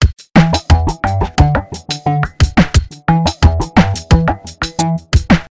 Малый барабан и басс приводят к всплеску амплитуды
Когда малый барабан и басс попадают в одну долю, происходит всплекск амплитуды на 4 Дб.